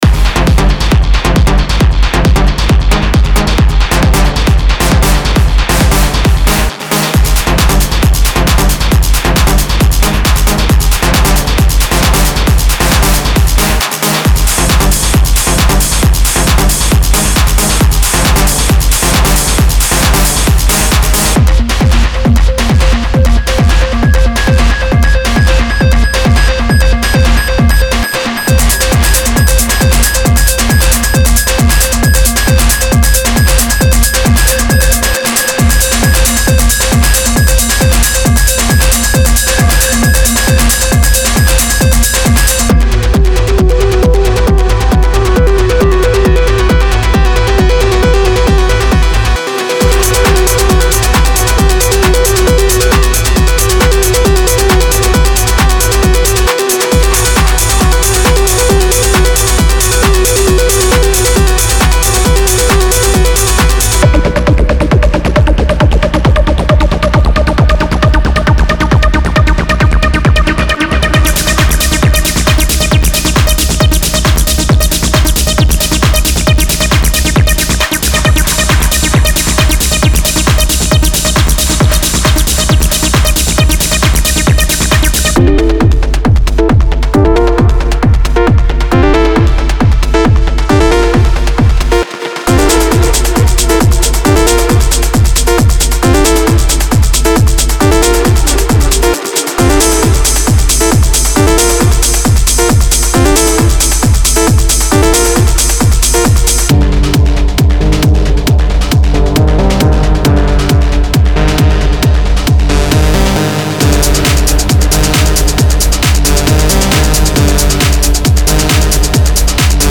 berlin techno styled sample pack
– Loops 135 BPM & Key Labeled